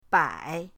bai3.mp3